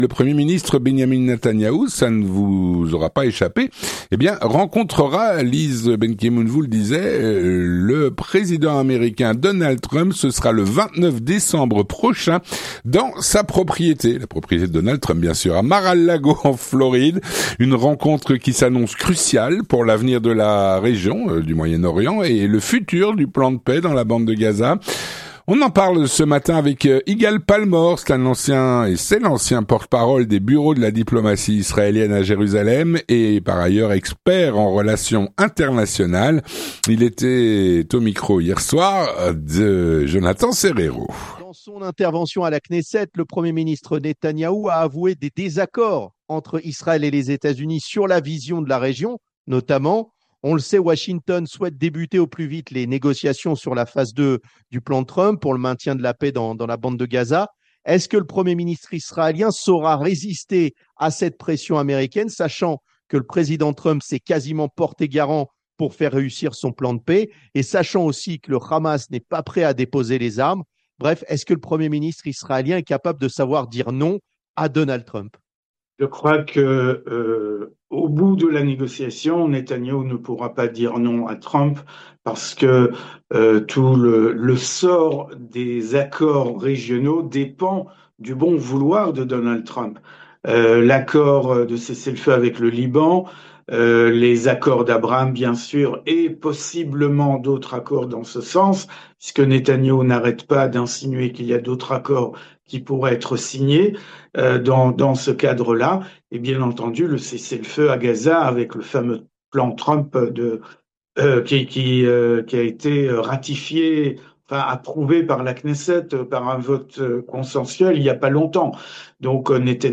L'entretien du 18h